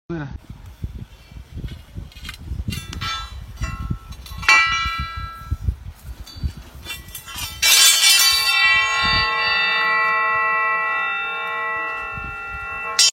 The sound of a sword sound effects free download